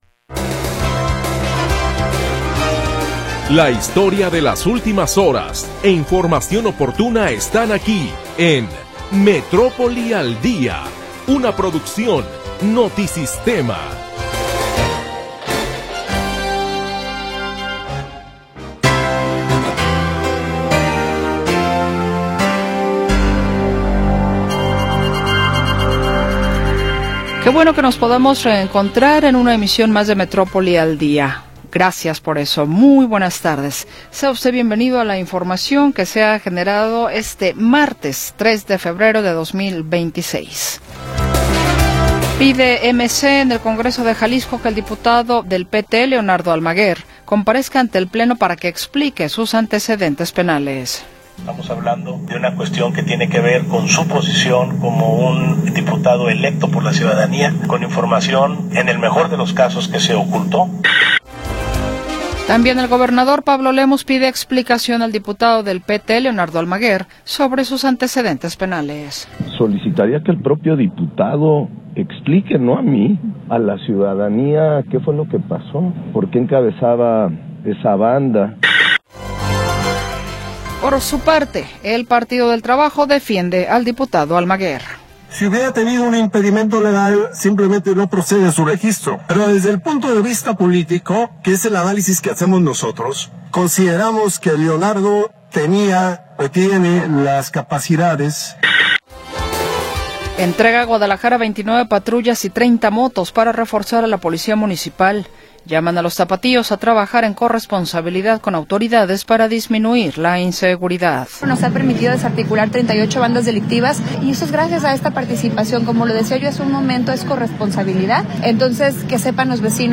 Primera hora del programa transmitido el 3 de Febrero de 2026.